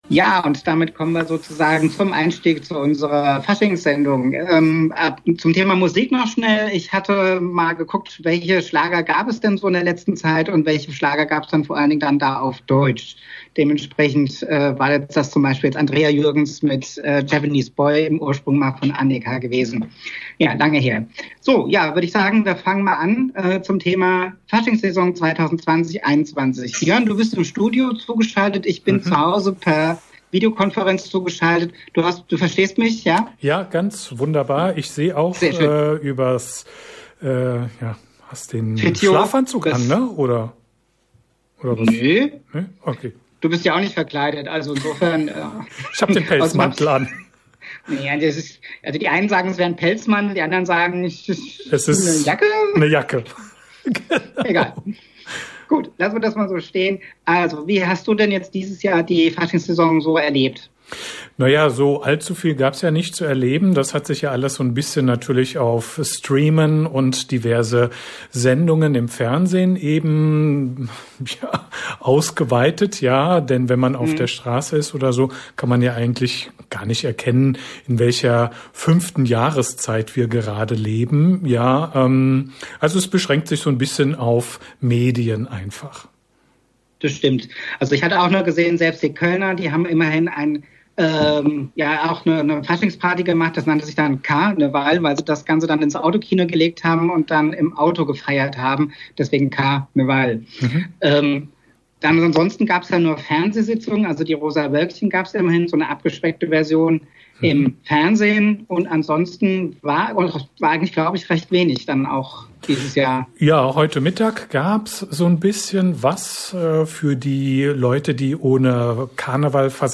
Helau! - Talk zur Faschingssession 2020/2021